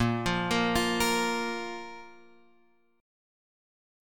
A#5 chord {6 x 3 3 6 6} chord